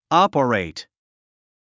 operate 発音 ɑ’pərèit アパレイト